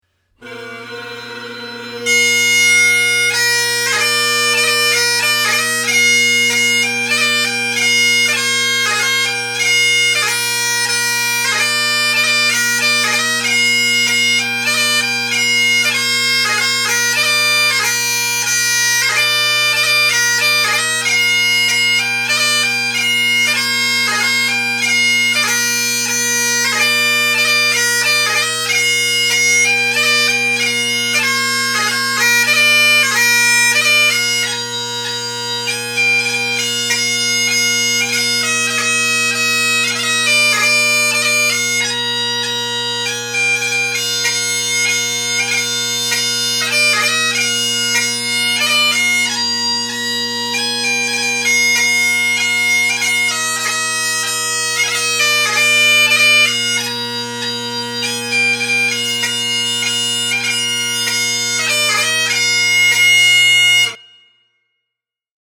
Halifax Bagpiper